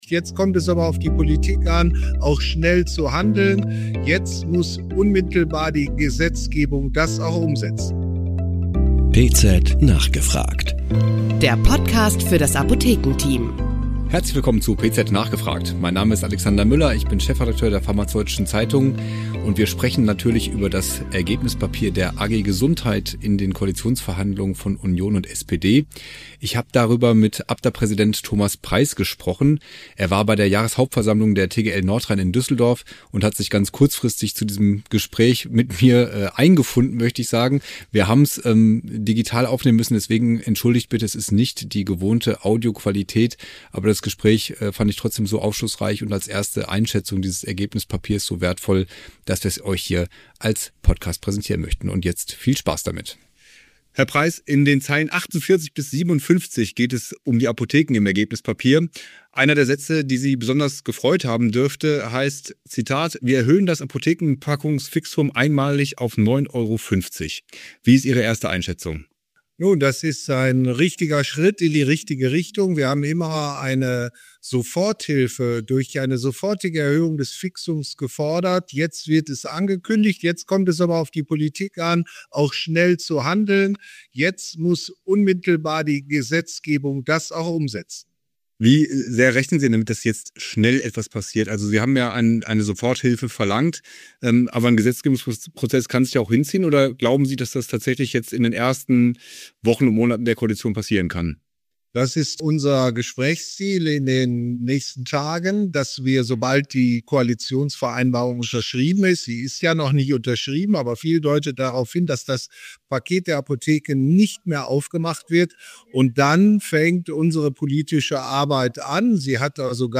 Zwei Studierende berichten von ihren Erfahrungen mit dem interprofessionellen Masterstudiengang Arzneimitteltherapiesicherheit.